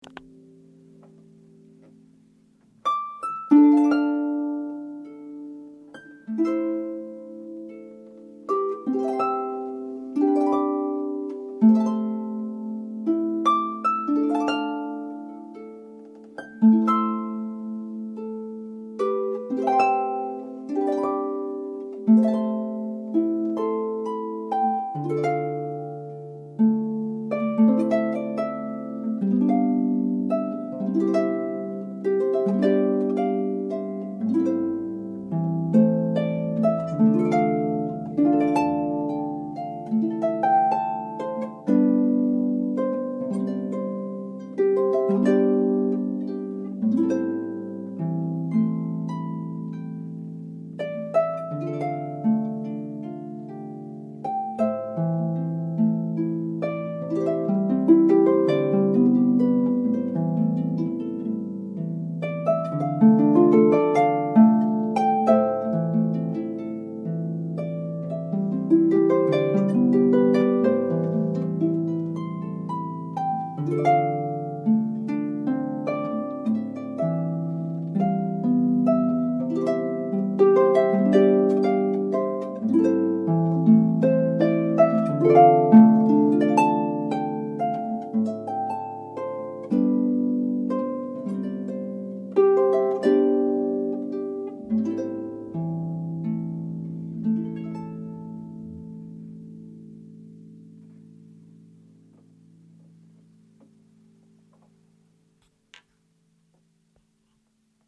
harp, wedding harp,